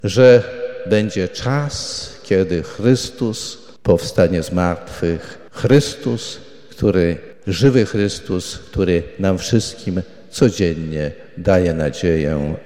Biskup Janusz Stepnowski przed południem przewodniczył MszyŚwiętej w łomżyńskiej katedrze.